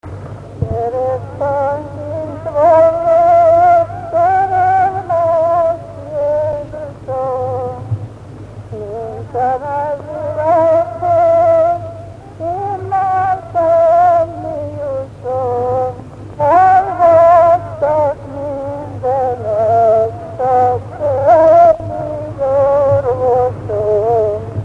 Dunántúl - Sopron vm. - Szany
Stílus: 8. Újszerű kisambitusú dallamok
Szótagszám: 12.12.12
Kadencia: 2 (2) 1